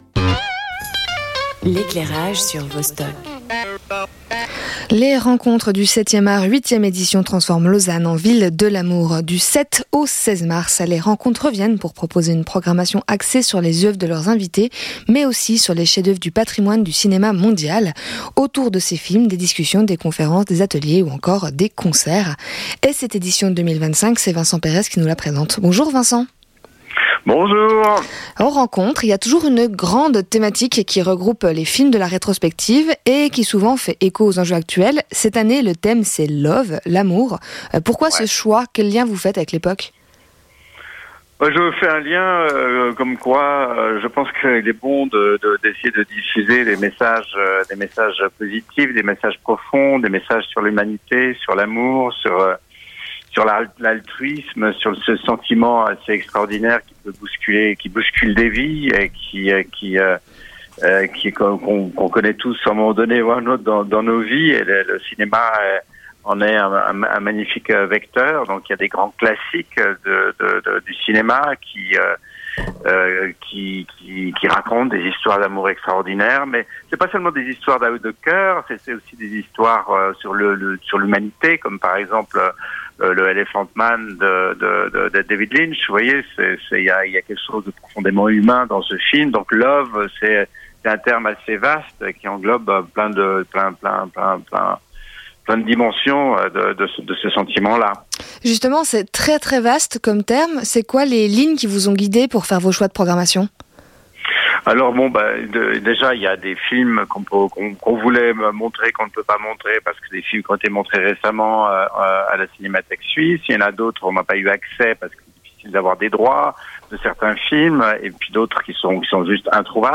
Invité : Vincent Perez